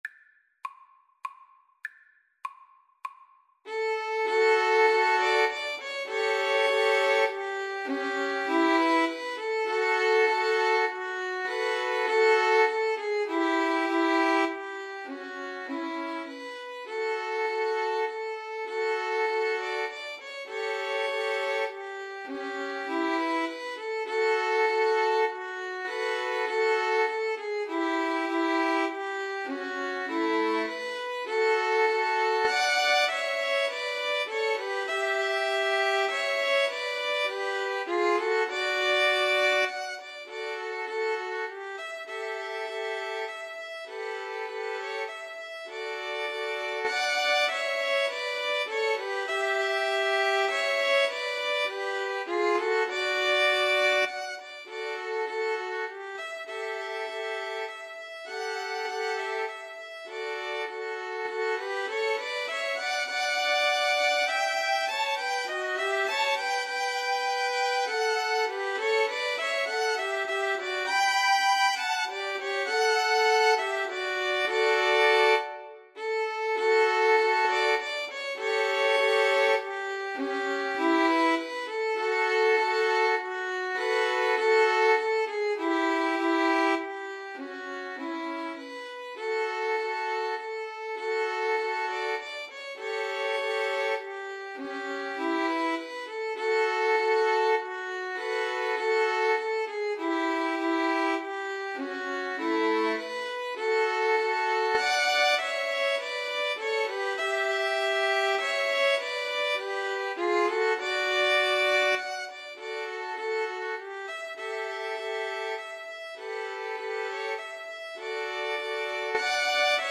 2-Violins-Cello version
Violin 1Violin 2Cello
Andantino (View more music marked Andantino)
3/4 (View more 3/4 Music)